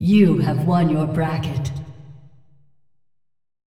vo-anncr-fem1-tournaments-win-bracket-generic-01.ogg